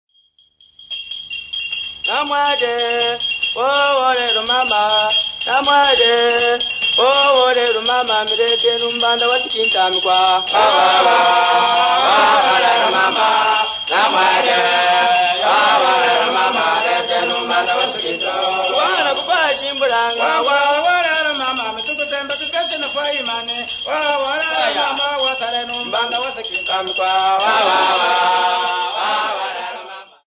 Hugh Tracey
Folk music--Africa
Field recordings
sound recording-musical
The Sinkinta dance is said to be the favourite Luunda dance of the present day (1952). This set of Sikinta dance tunes was recorded by young Luunda men who were working on the Roan Antelope Copper Mine. Four Sikinta dance song with struck bottles